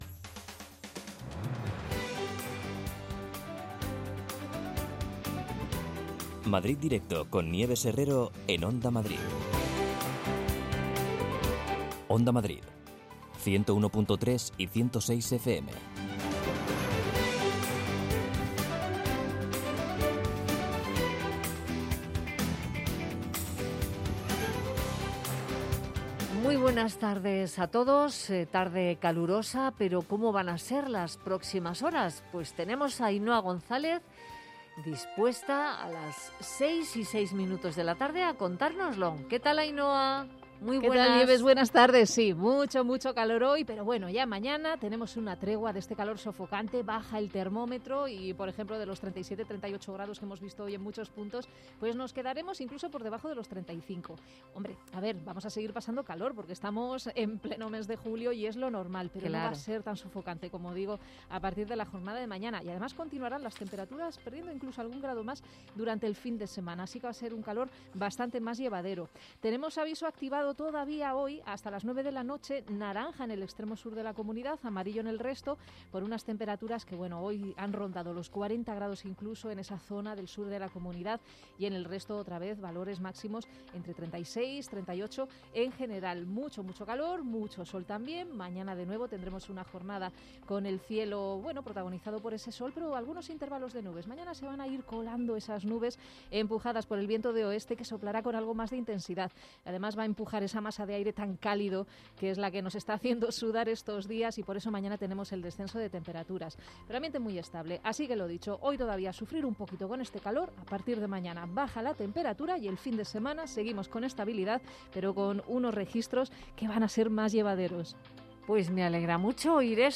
Nieves Herrero se pone al frente de un equipo de periodistas y colaboradores para tomarle el pulso a las tardes. Cuatro horas de radio donde todo tiene cabida.